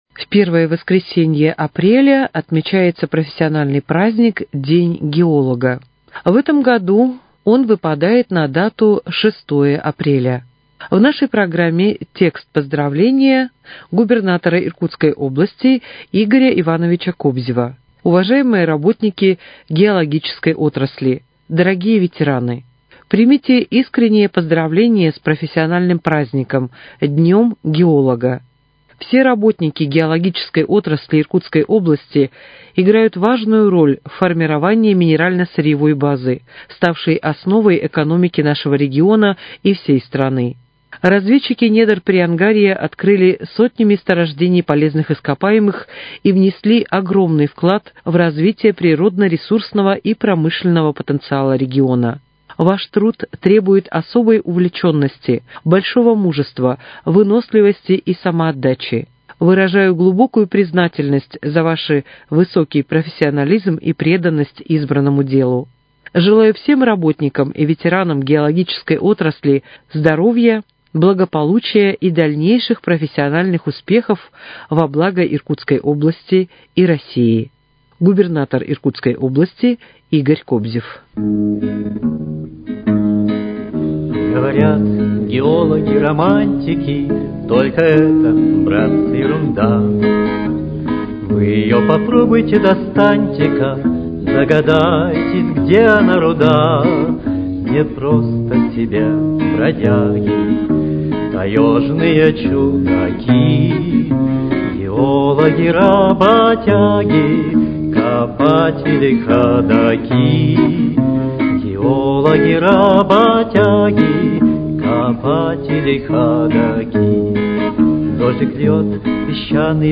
Поздравление мэра Иркутска Руслана Болотова со Всемирным днём здоровья, который отмечается в этом году 7 апреля.